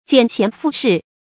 簡賢附勢 注音： ㄐㄧㄢˇ ㄒㄧㄢˊ ㄈㄨˋ ㄕㄧˋ 讀音讀法： 意思解釋： 輕視賢良，依附權勢。